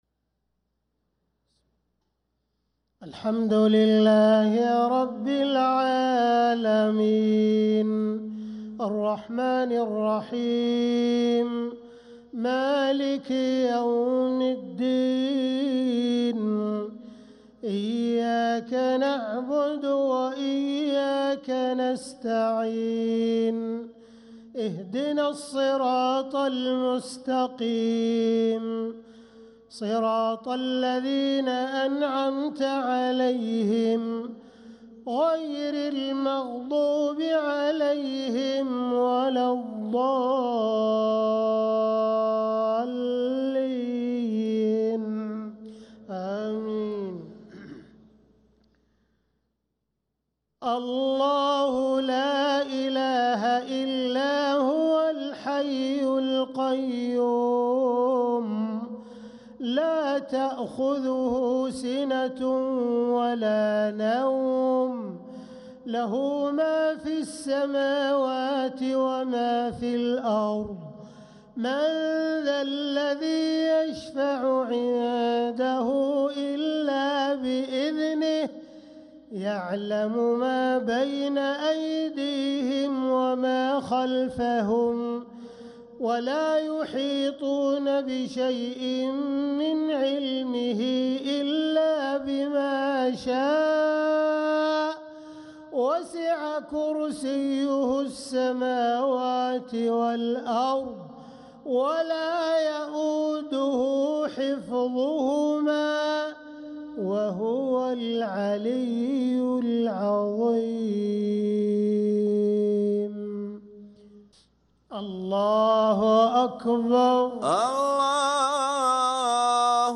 صلاة العشاء للقارئ عبدالرحمن السديس 25 ربيع الآخر 1446 هـ
تِلَاوَات الْحَرَمَيْن .